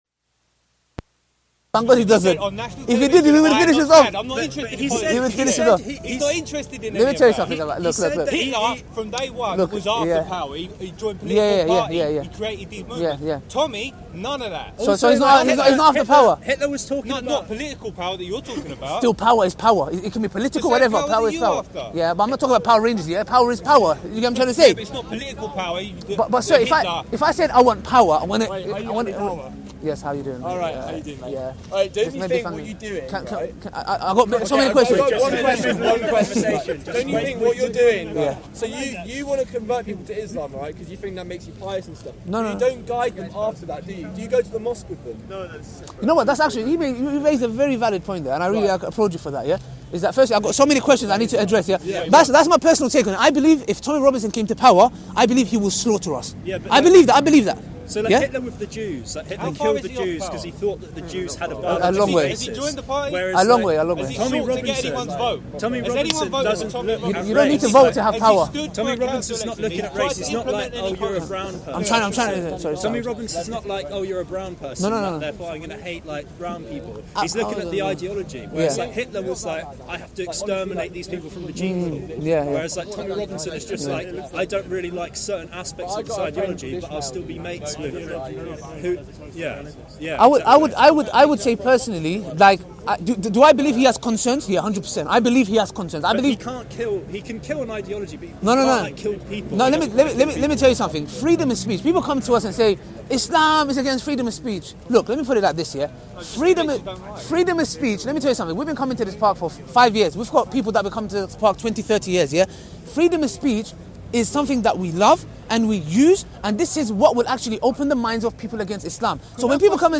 5 GUYS WITH MULTIPLE QUESTIONS - SPEAKERS CORNER.mp3